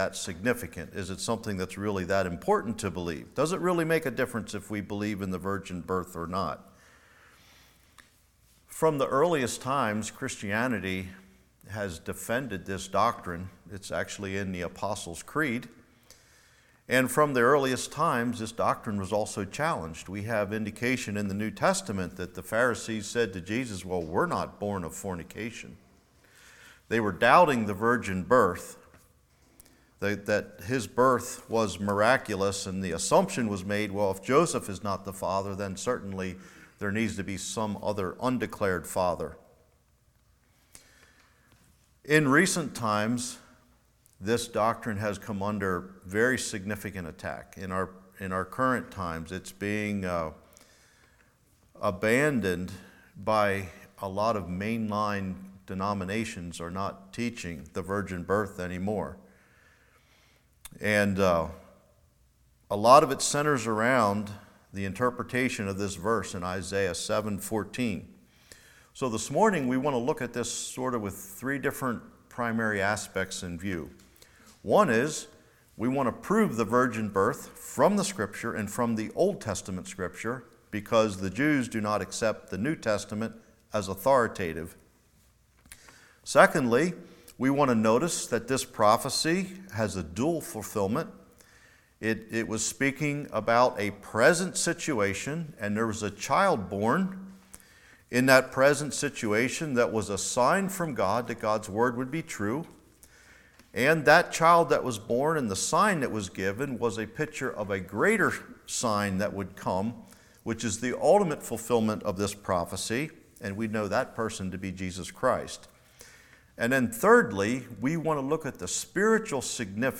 Isaiah 7:11-20 Service Type: Morning The explanation of the Virgin Birth from the Old Testament.